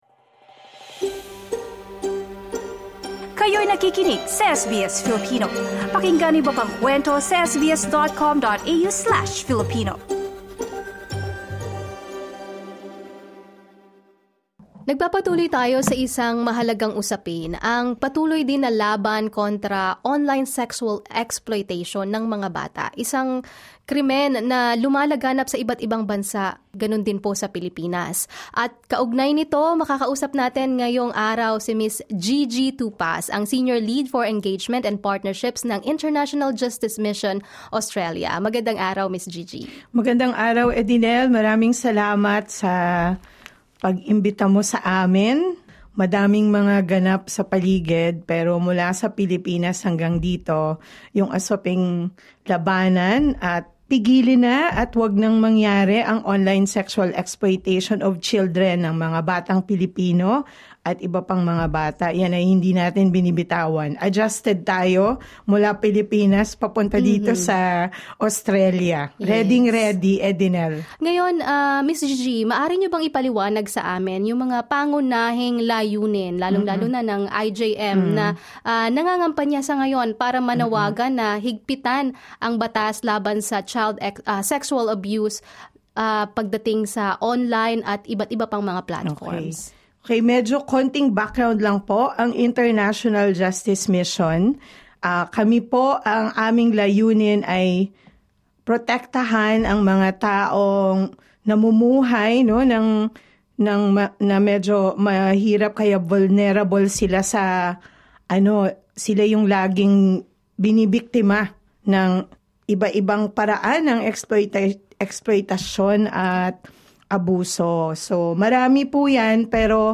Key Points In an interview